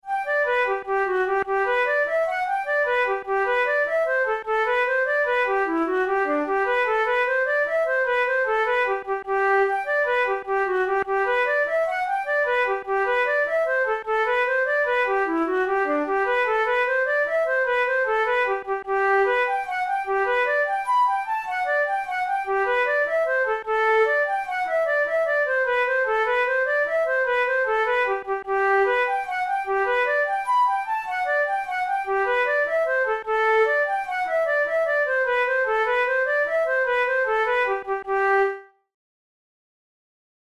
InstrumentationFlute solo
KeyG major
Time signature6/8
Tempo100 BPM
Jigs, Traditional/Folk
Traditional Irish jig
come-along-with-me-jig.mp3